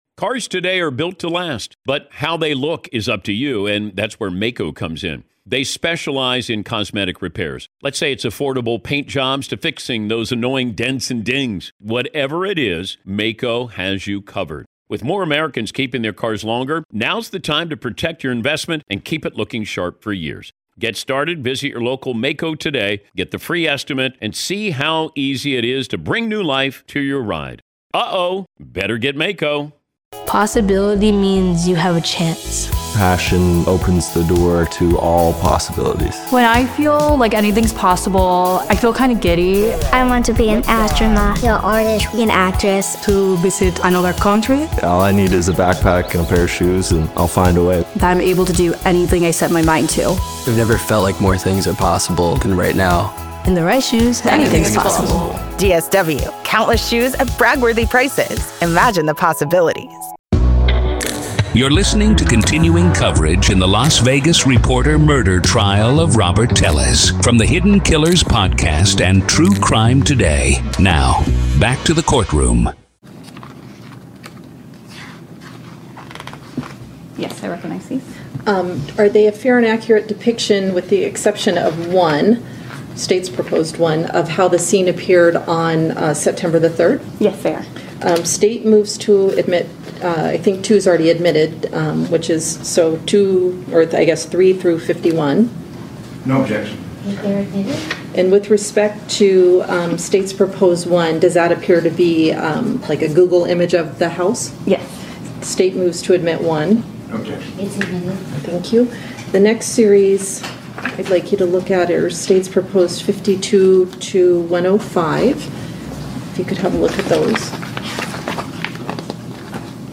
Court Audio-NV v. Robert Telles DAY 1 Part 3